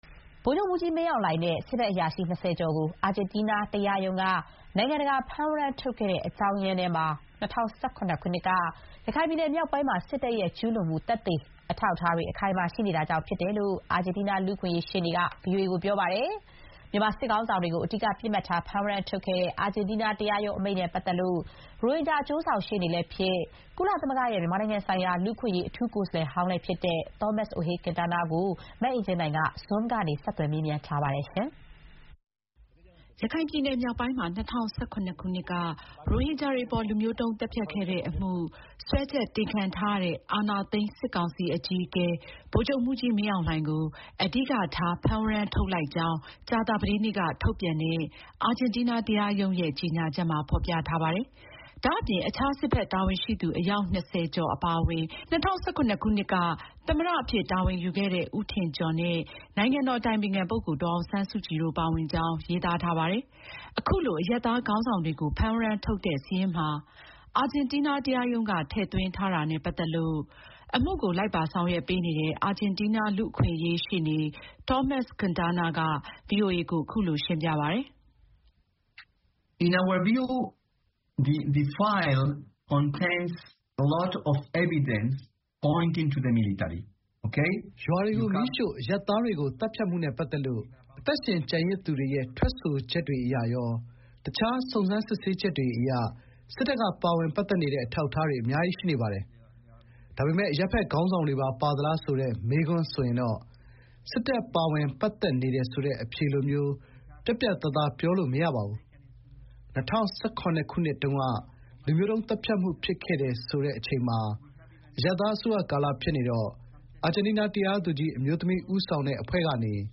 Zoom ကနေ ဆက်သွယ် မေးမြန်းထားပါတယ်။